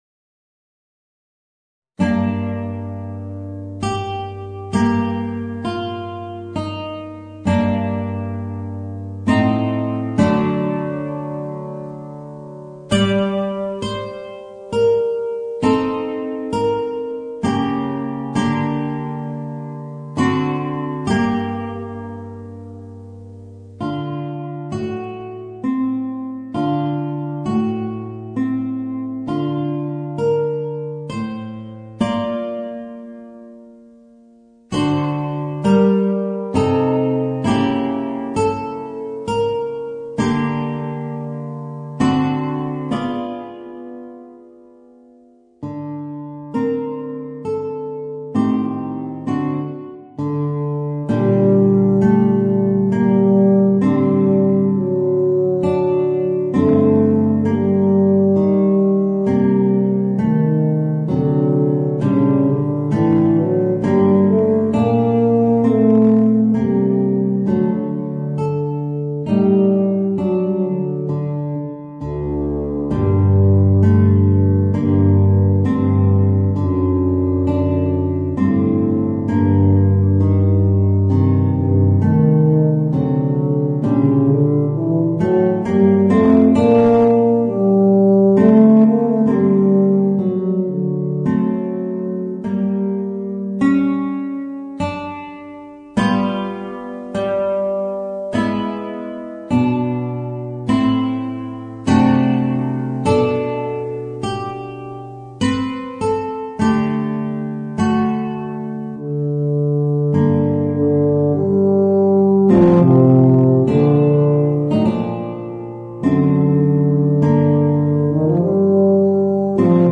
Voicing: Eb Bass and Guitar